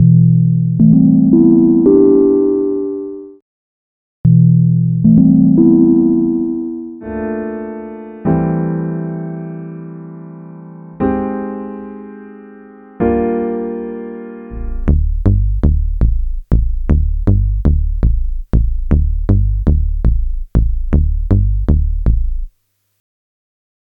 HIGH QUALITY – Each pack contains 10 WAV format loops to ensure the highest quality and are a breeze to drag and drop into your preferred digital audio workstation (DAW).